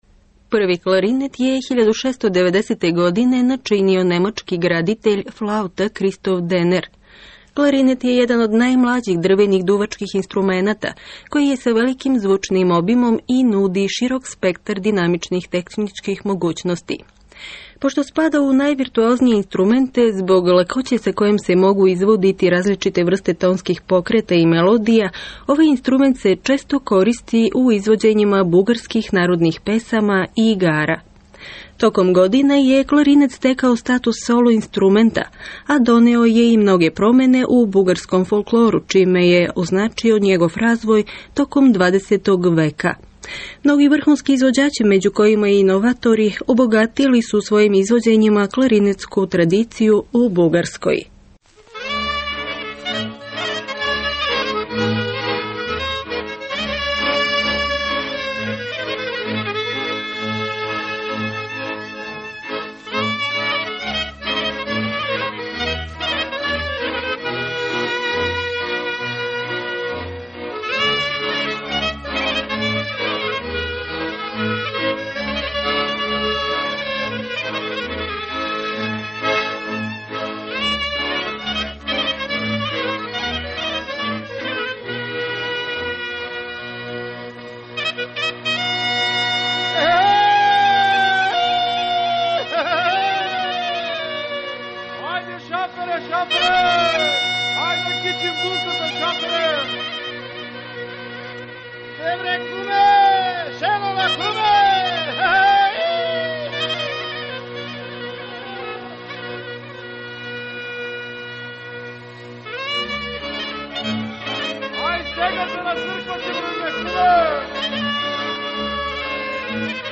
Магија кларинета у бугарској традиционалној музици